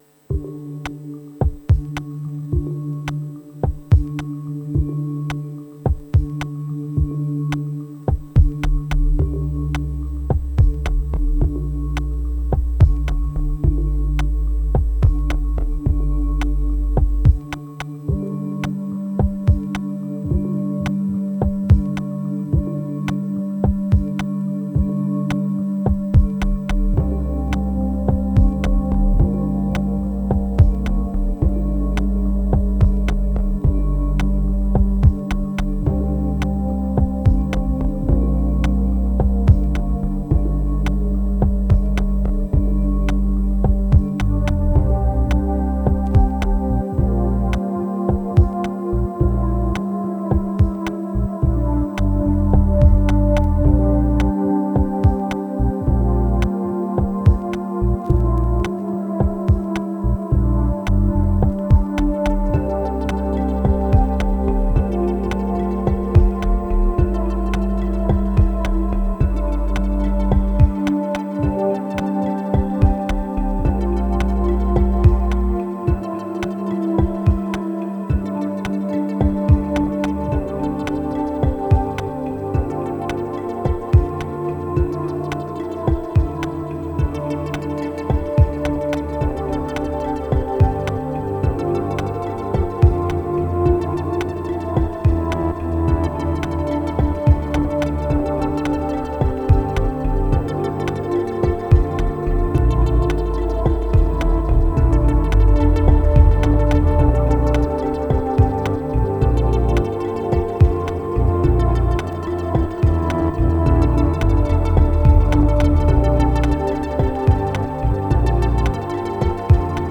1072📈 - 86%🤔 - 54BPM🔊 - 2022-06-14📅 - 1237🌟